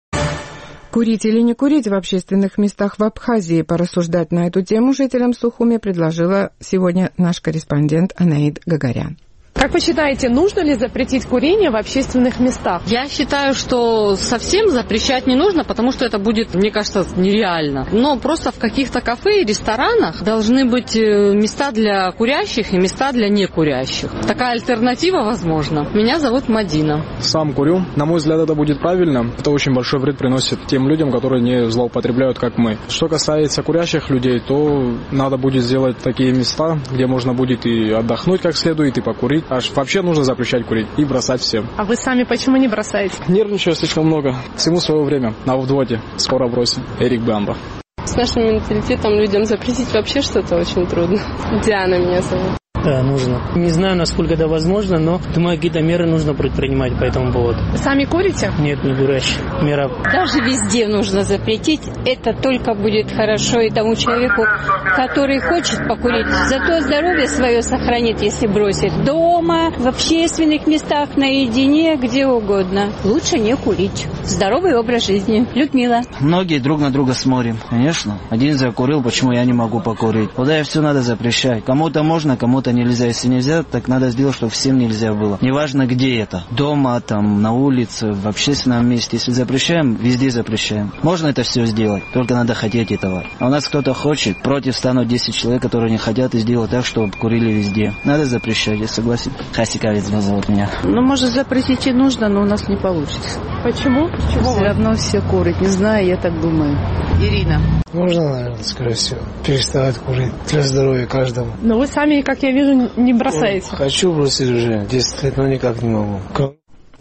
Наш сухумский корреспондент поинтересовался у жителей абхазской столицы, нужно ли запретить курение в общественных местах.